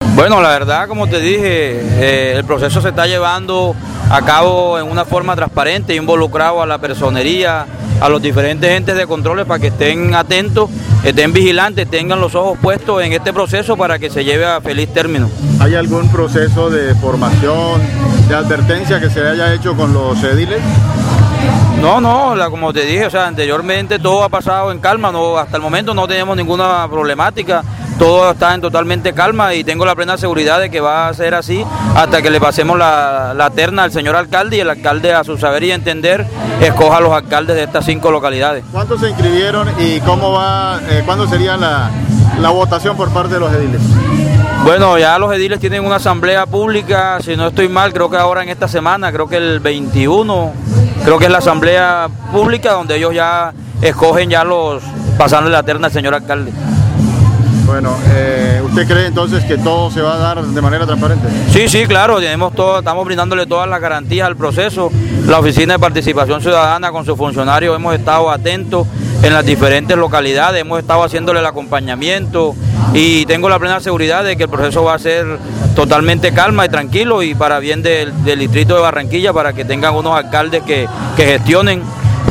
Quienes resulten escogidos por el alcalde comenzarán a ejercer el cargo de manera inmediata, precisó Roa en diálogo con Atlántico en Noticias.